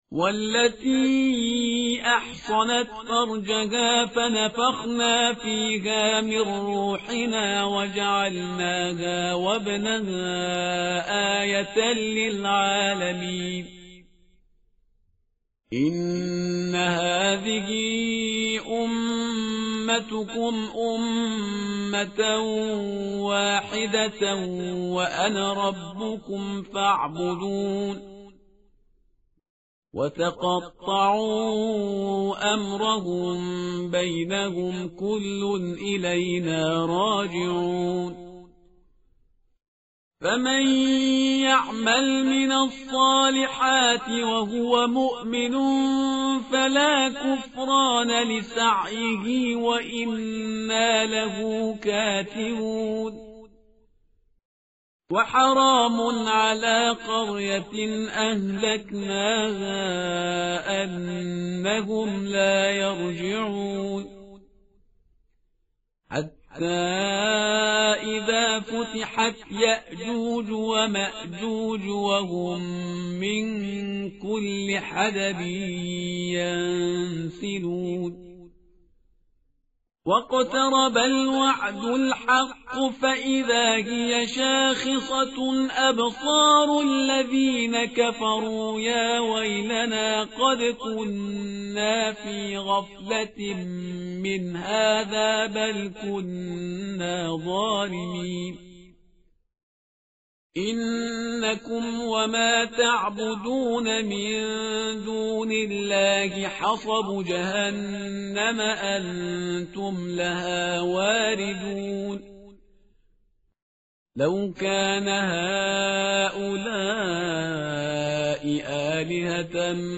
tartil_parhizgar_page_330.mp3